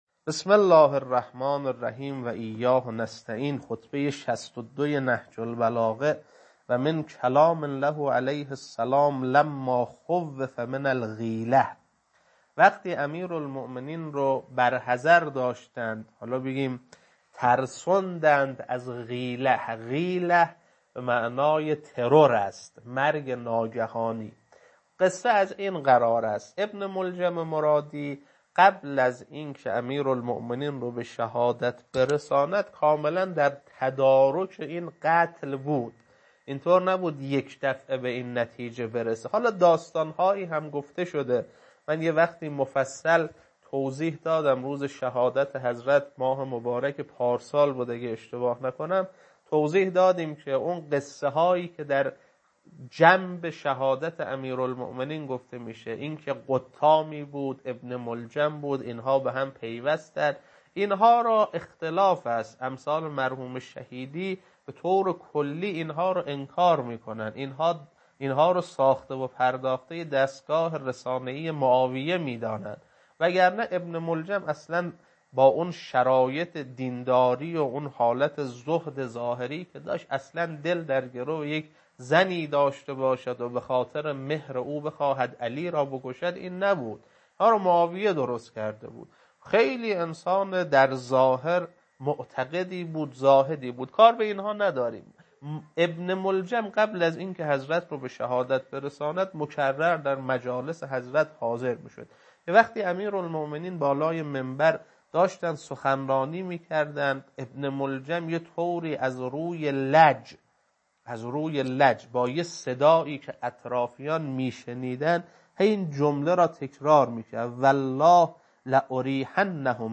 خطبه 62.mp3
خطبه-62.mp3